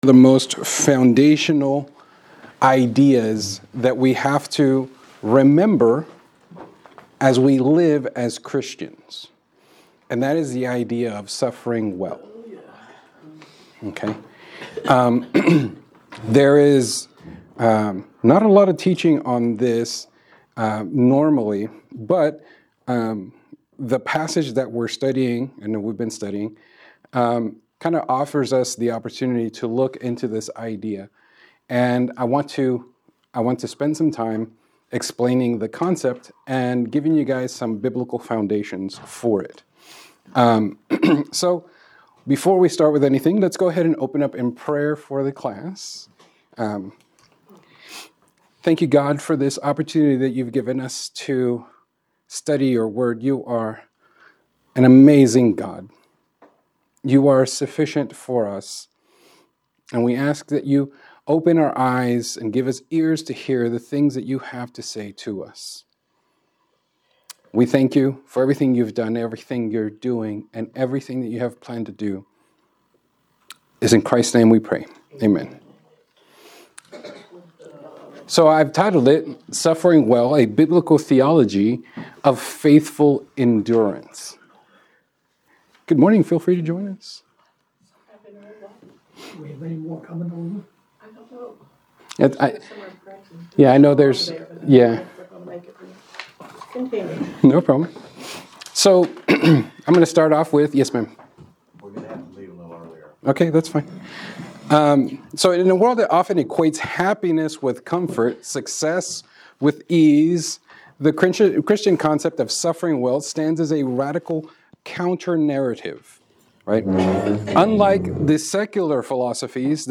Revelation 2:8-11 Part 1 Class Recording
Information for my Sunday School class, and friends of the church.